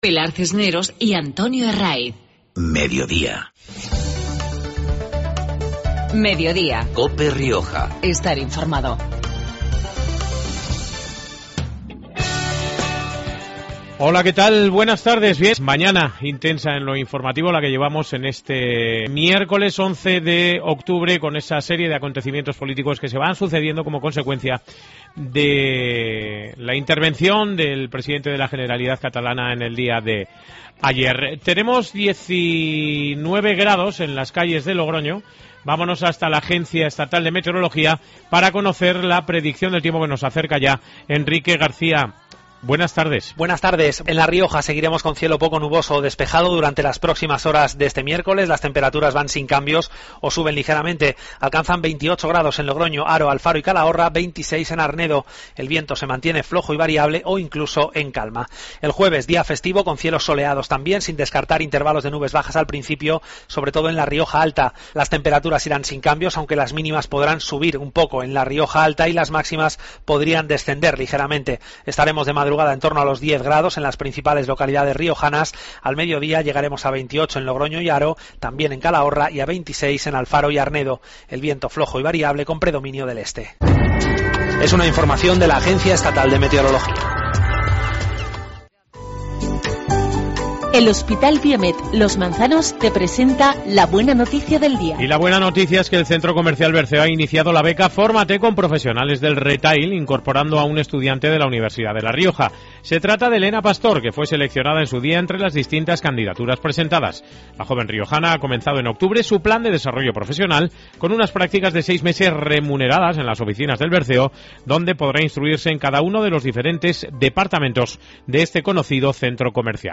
Programa regional de actualidad, entrevistas y entretenimiento.